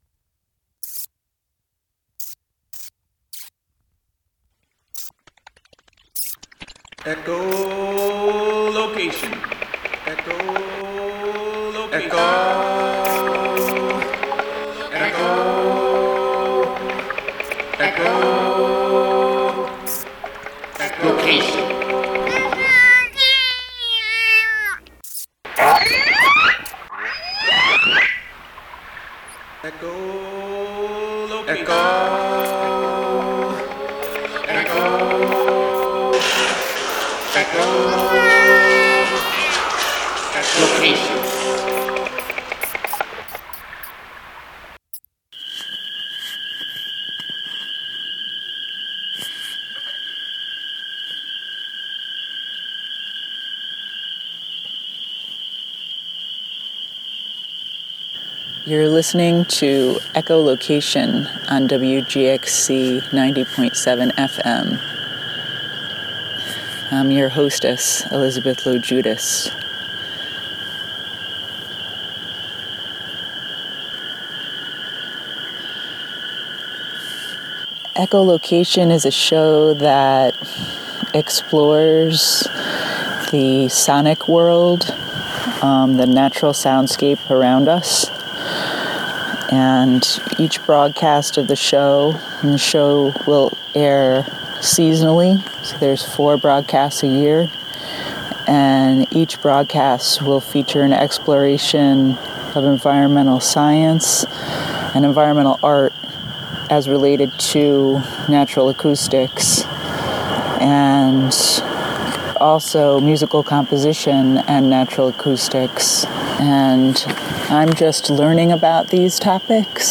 We will listen to excerpts from the Sound in Theory, Sound in Practice Symposium, which took place at Bard College in April, 2016. The show will feature musical selections, as well as the melodious and cacophonous sounds of spring in the Northeastern US.